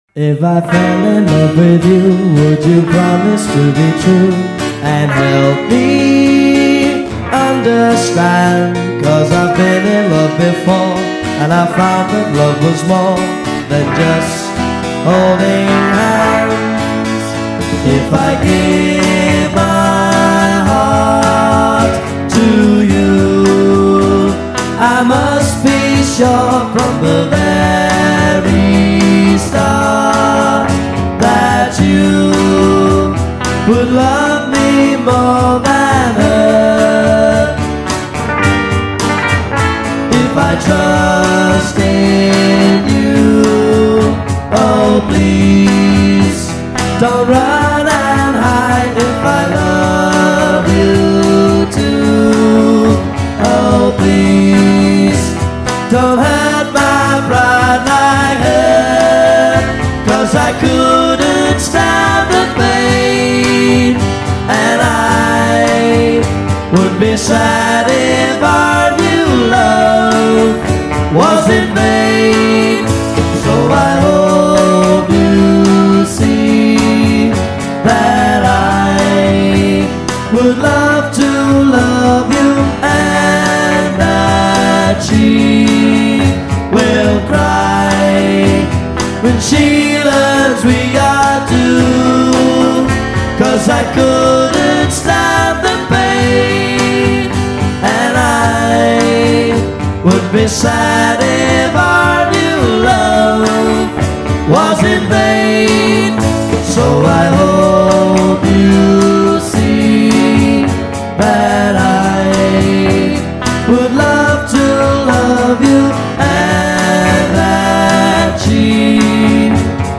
FULL COVER